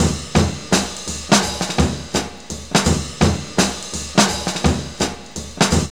JAZZLP7 84.wav